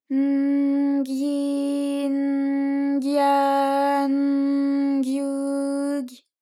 ALYS-DB-001-JPN - First Japanese UTAU vocal library of ALYS.
gy_n_gyi_n_gya_n_gyu_gy.wav